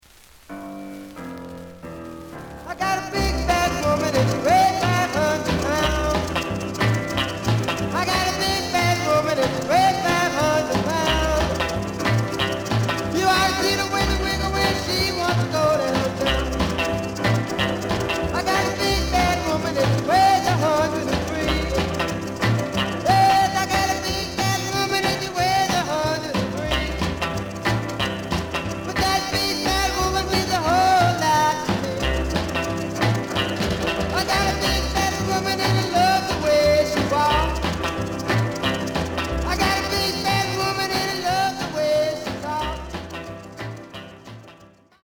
The audio sample is recorded from the actual item.
●Genre: Rhythm And Blues / Rock 'n' Roll
B side plays good.)